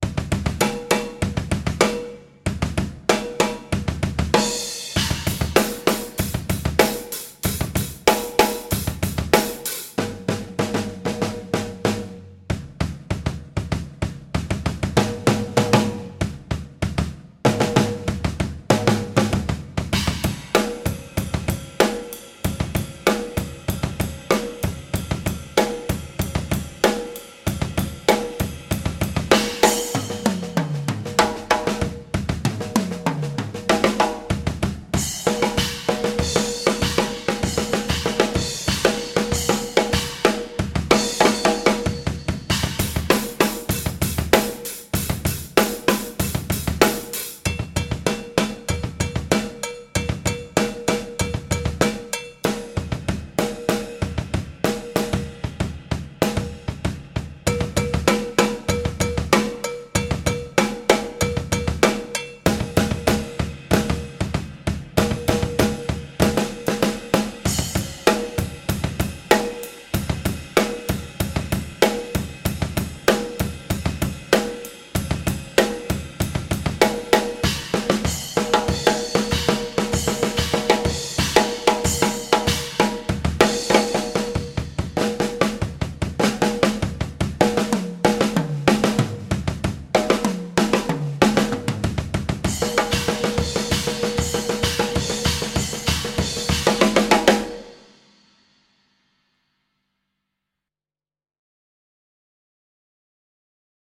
Drum Walk